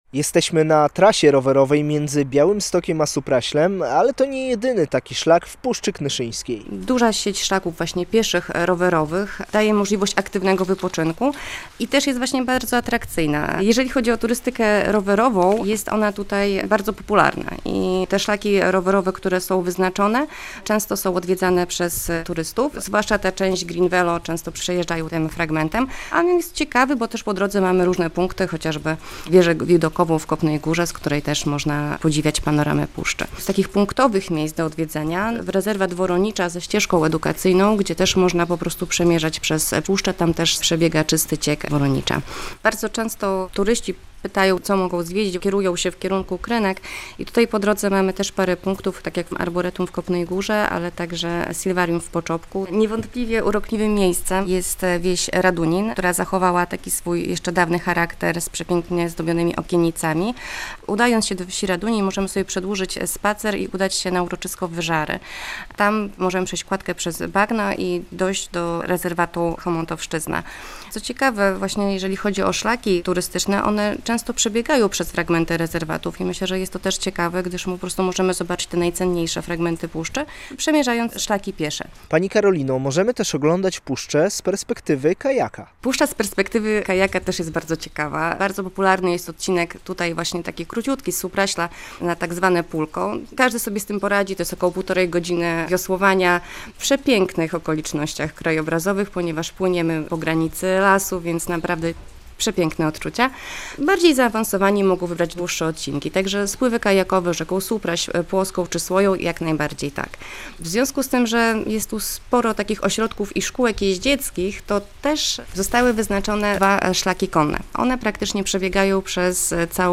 Odwiedzamy rezerwat przyrody Krzemianka w Parku Krajobrazowym Puszczy Knyszyńskiej - relacja